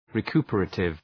Shkrimi fonetik{rı’ku:pə,reıtıv}
recuperative.mp3